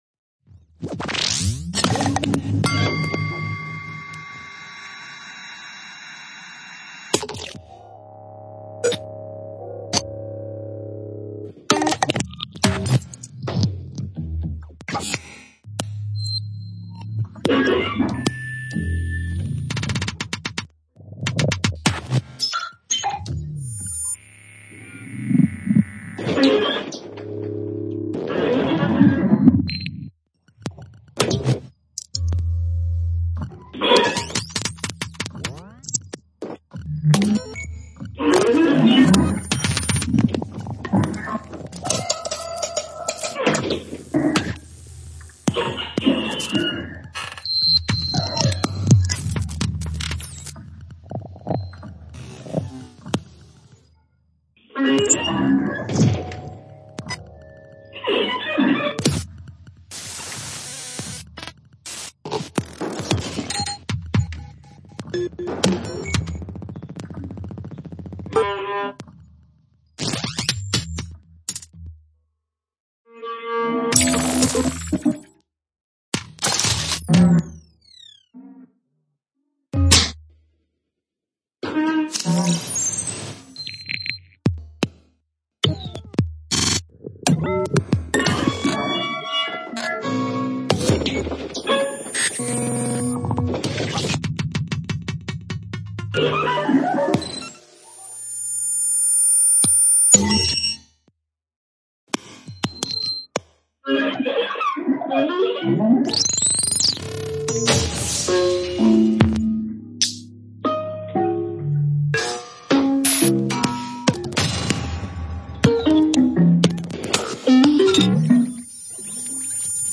FILE São Paulo 2019 | Hipersonica
O segundo movimento de ‘Disgression n° 2’ foi composto a partir da ansiedade criativa, da exploração sonora, dos sons digitais, gravações de campo, instrumentos acústicos, eletroacústicos e virtuais em busca de estabelecer um diálogo com os limites da inconsciência.